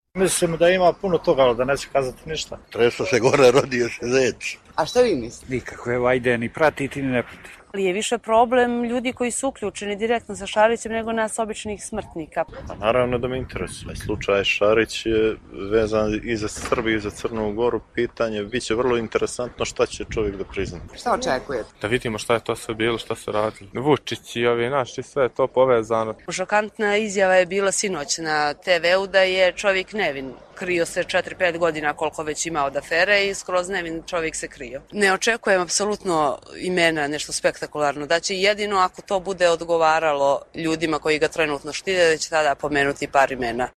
Nikšićani o slučaju Šarić